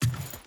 Wood Chain Jump.ogg